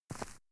footstep_earth_left.ogg